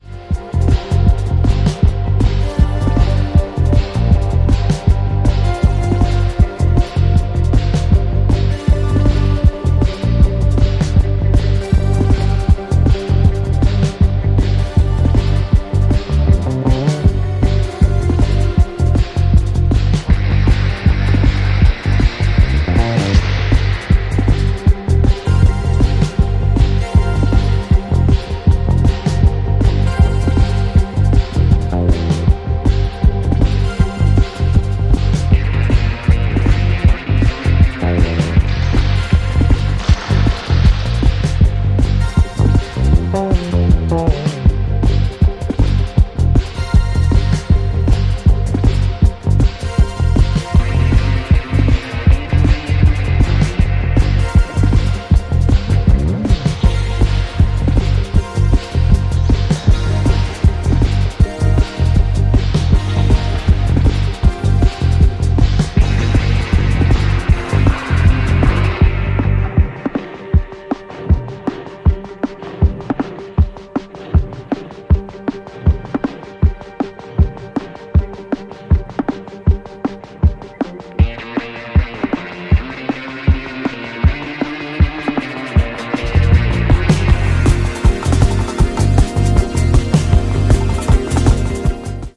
ジャンル(スタイル) JAZZ